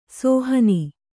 ♪ sōhani